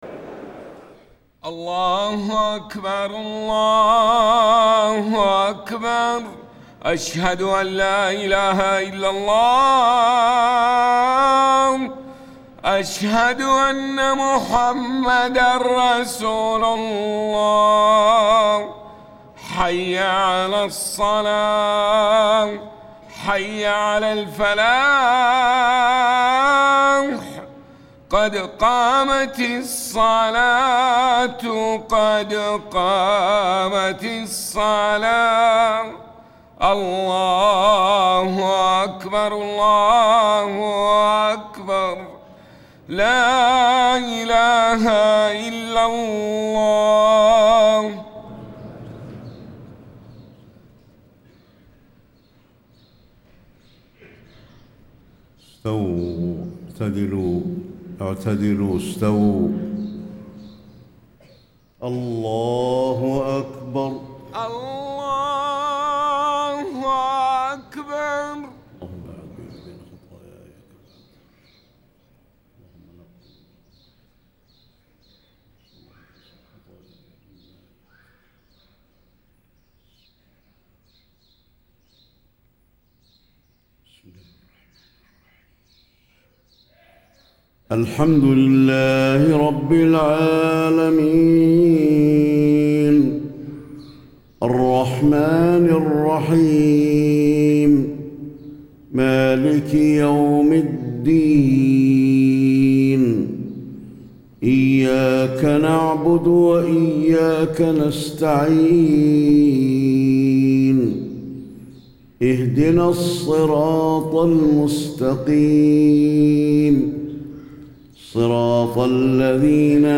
صلاة الفجر 6-5-1435 سورتي السجدة والإنسان > 1435 🕌 > الفروض - تلاوات الحرمين